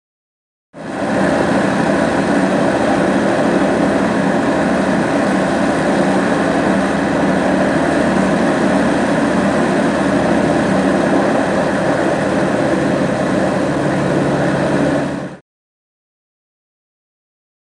Mosquito|Ext | Sneak On The Lot
Prop Plane; Idle; Mosquito Prop Aircraft Constant Engines. Ok For Interior Or Exterior.